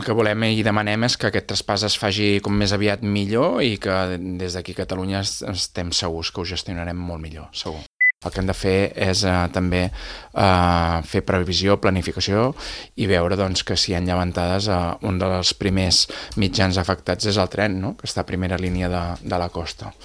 Ho ha afirmat el portaveu republicà i cap de l’oposició, Xavier Ponsdomènech, a l’Entrevista Política de Ràdio Calella TV.